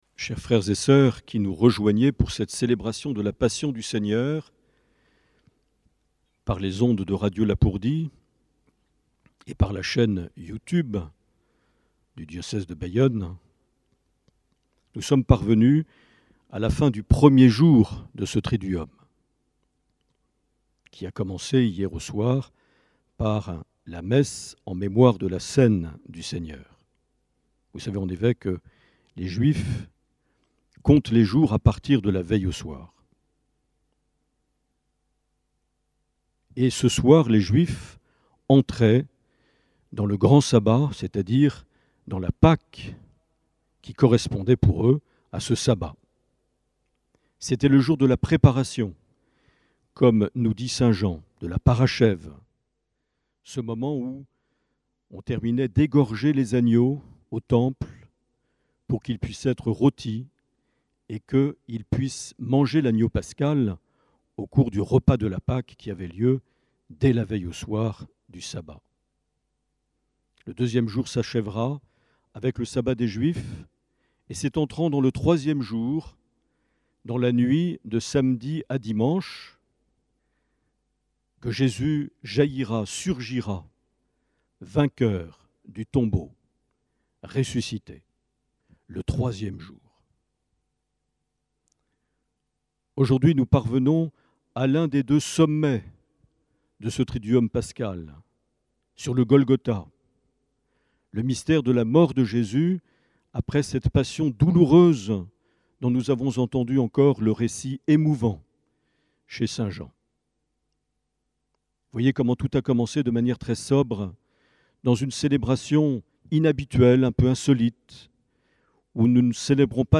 10 avril 2020 - Vendredi Saint - Cathédrale de Bayonne
Les Homélies
Une émission présentée par Monseigneur Marc Aillet